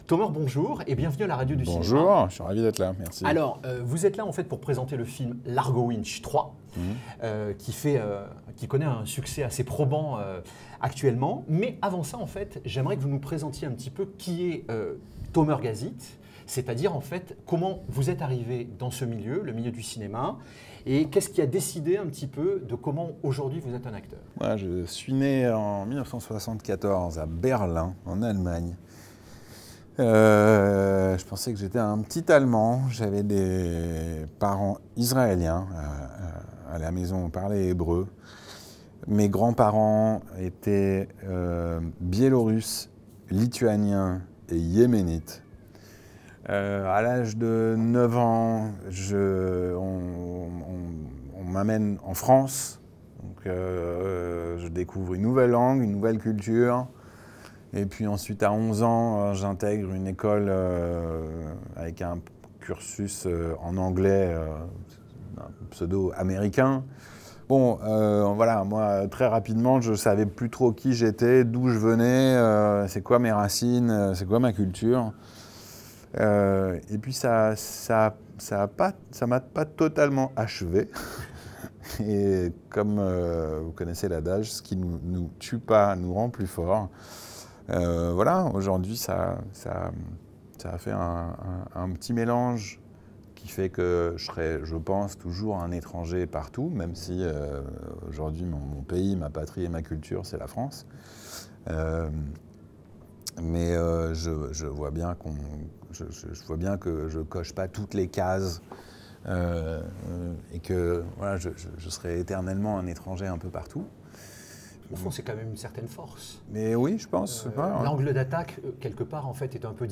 lors du Festival de la Baule 2024.